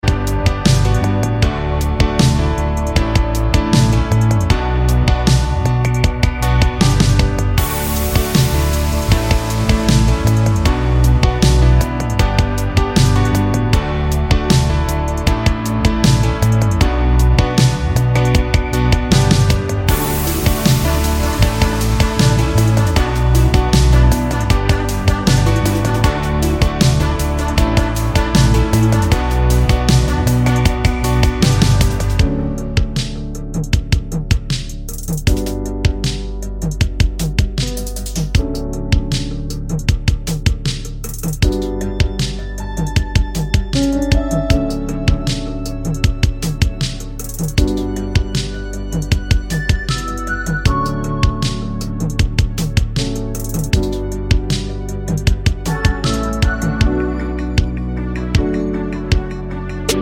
no Backing Vocals but With Sample Finnish 3:41 Buy £1.50